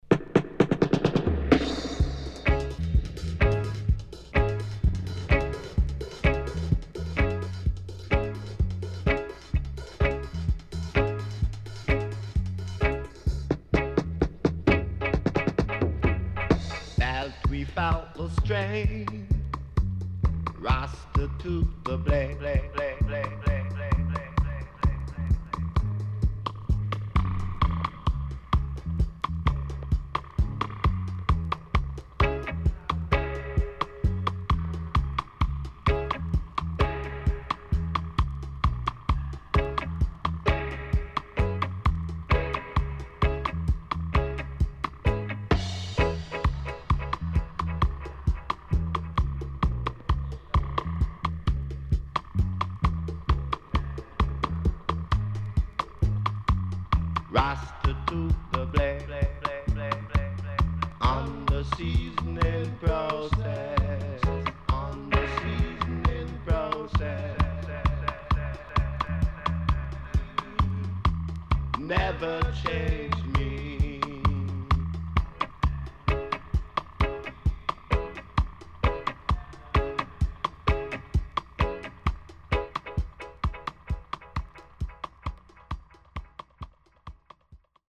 HOME > DUB
SIDE A:少しチリノイズ入りますが良好です。